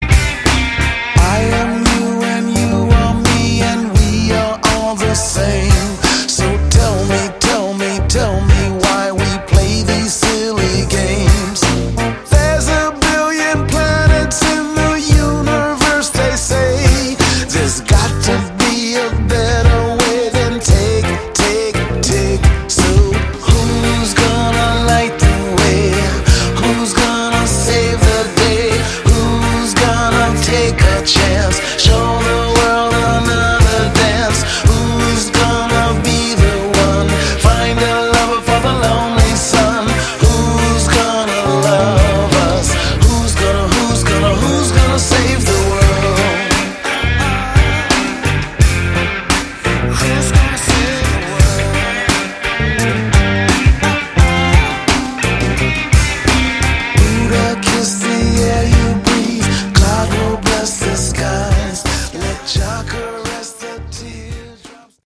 Category: Melodic Rock
Far too mellow.